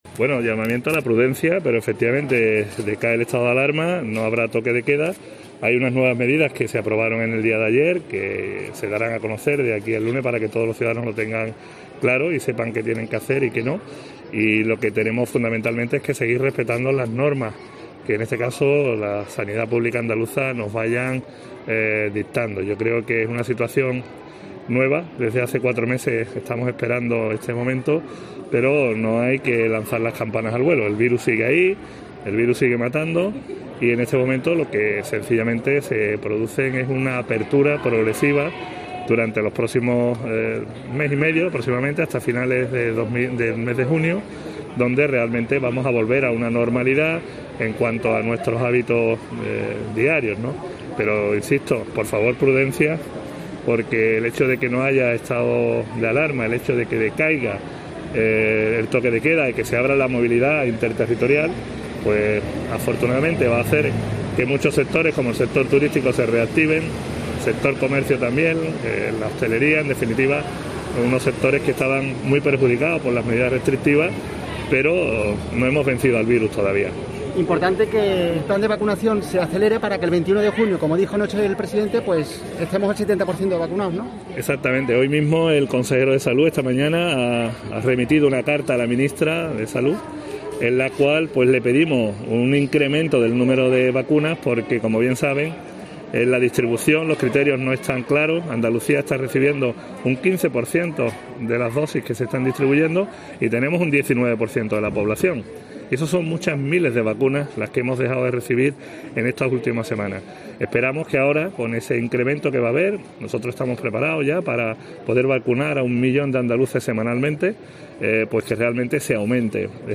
En este sentido y en declaraciones a los periodistas en Córdoba, poco antes de participar junto al alcalde de la ciudad, José María Bellido, y con la asistencia de la delegada de Turismo de la Diputación cordobesa, Inmaculada Silas, en el Foro '100 años de los Patios de Cordoba', organizado por ABC, Marín ha dicho que "desde hace cuatro meses estamos esperando este momento, pero no hay que lanzar las campanas al vuelo" porque "el virus sigue ahí y el virus sigue matando".